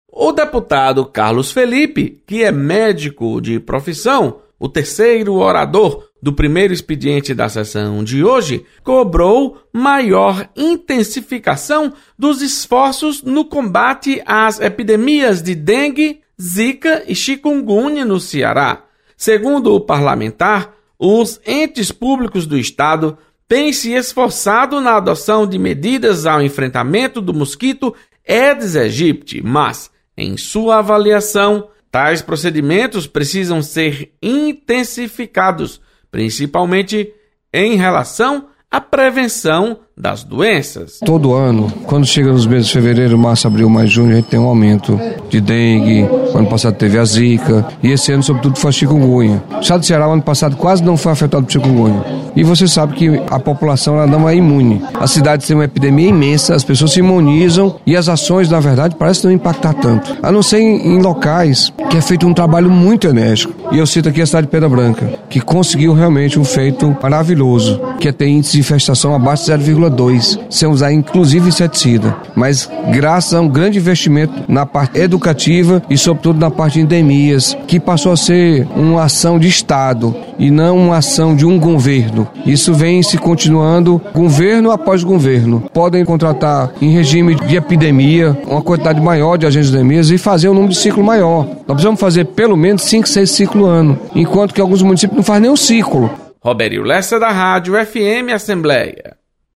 Deputado Carlos Felipe cobra intensificação de ações de combate mosquito Aedes aegypti. Repórter